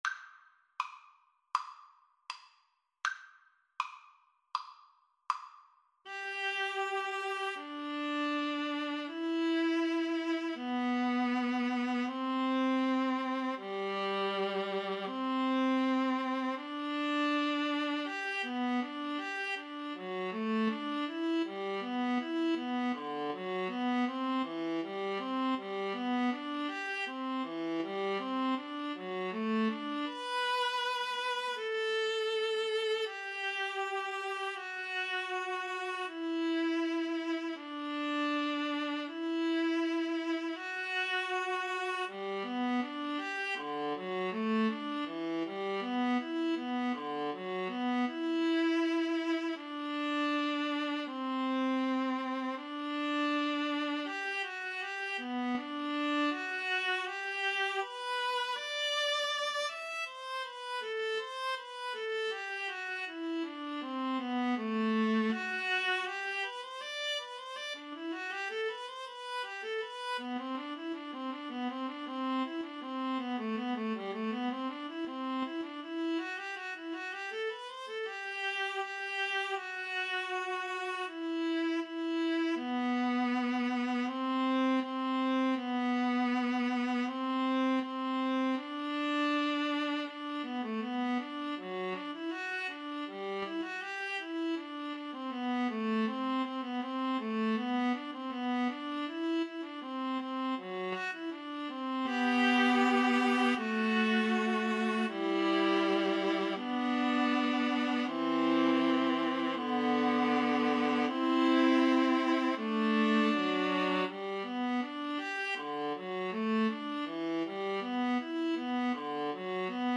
Andante